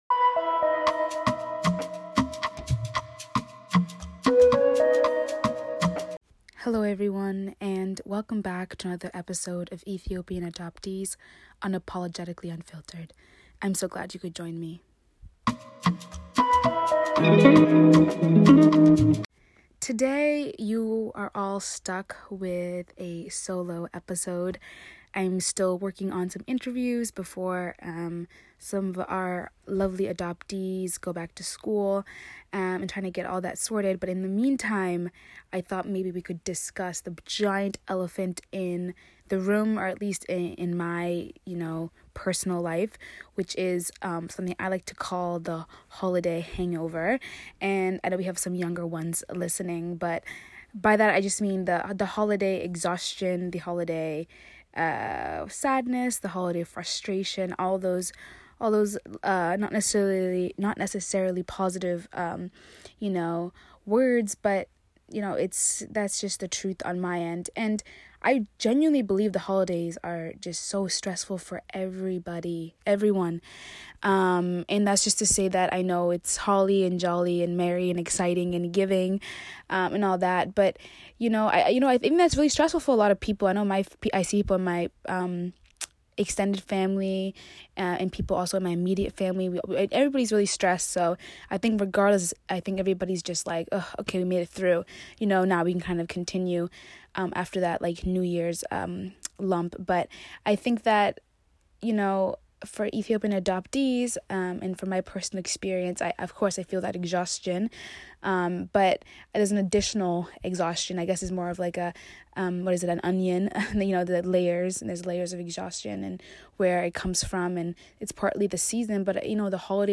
In this shorter solo episode, I talk about the emotional complexities of an Ethiopian adoptee during the holidays. The holidays are a time that really emphasizes family in a very biological sense and it can be (unintentionally) an alienating time for adoptees.